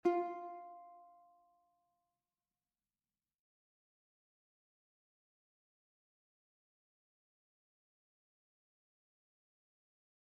UKELELE CORDA 4,3,2,1 | Flashcards
Fa3 (audio/mpeg)